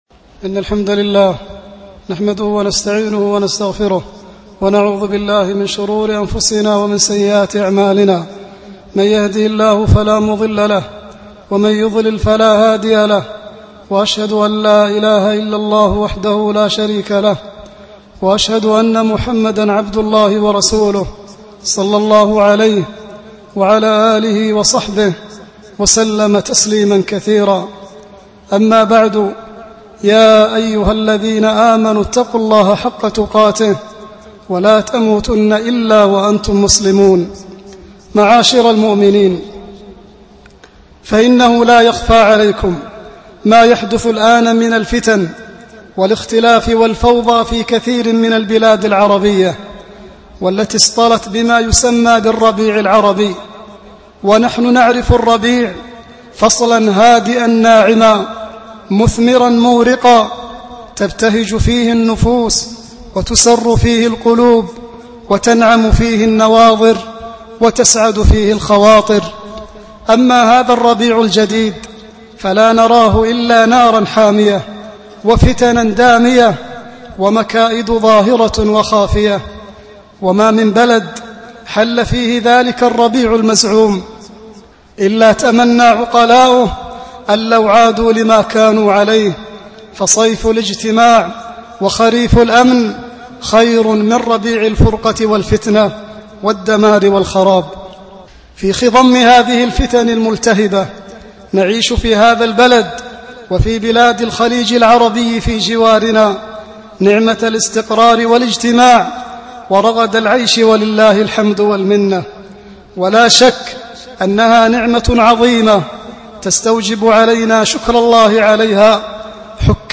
التهييج والإثارة Album: موقع النهج الواضح Length: 12:15 minutes (2.88 MB) Format: MP3 Mono 22kHz 32Kbps (VBR)